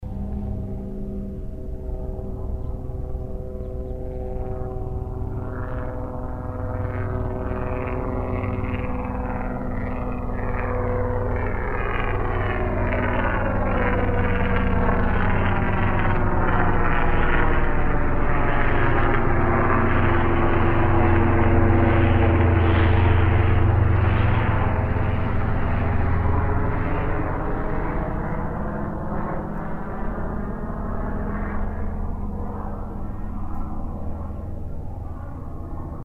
Robinson R22 Helicopter Flyover.
The blade passing frequency is 18 Hz.